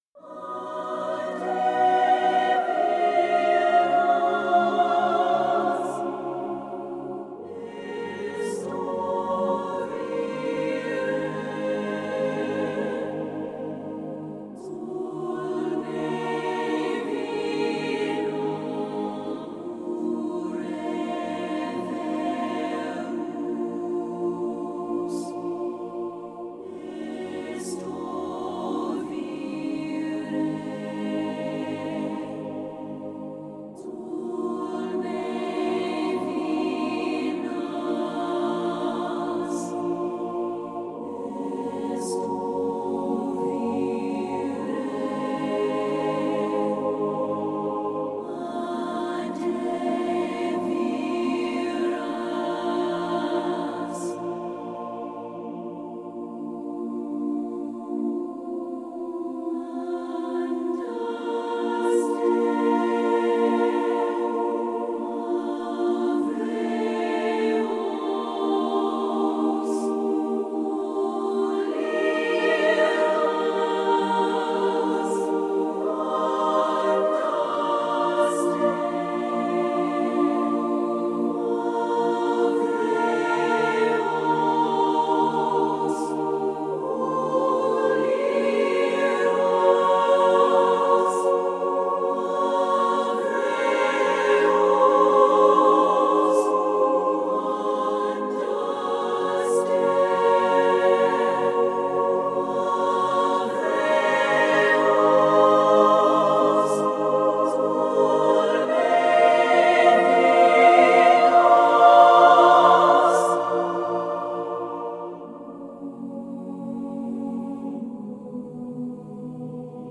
8Dio The New Studio Sopranos 是一款由 8Dio 出品的 Kontakt 5 库，它包含了三位极具天赋的女高音歌手的声音。这些声音是在干净的录音室环境中录制的，拥有非常清晰和细致的音质，也有很大的灵活性，可以控制声部、混响和音色。
The New Studio Sopranos 的声音具有很强的表现力和情感，可以在温柔和激烈之间自由切换，为你的配乐提供美丽的背景纹理，或者在混音中占据主导地位。
The New Studio Sopranos 的声音种类非常丰富，包括了真实连奏、多音连奏、弧线、断奏、强拍等等。
- 三位女高音歌手的声音